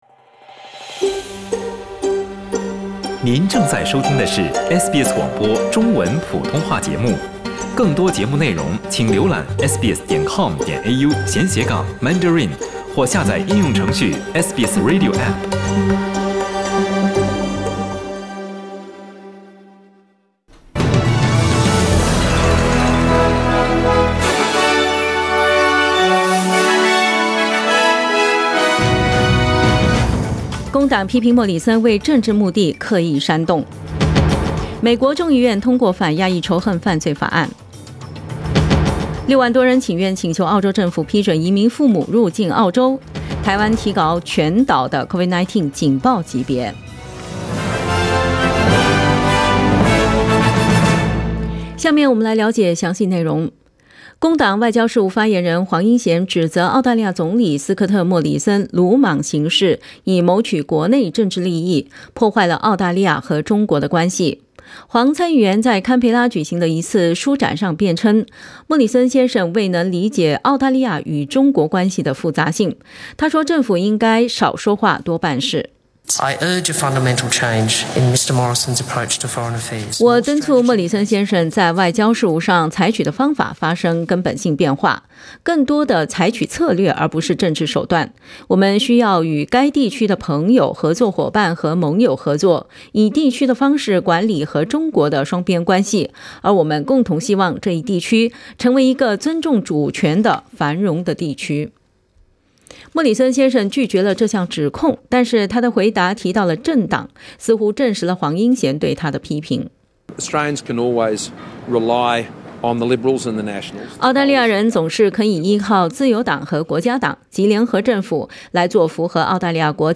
SBS早新聞（5月20日）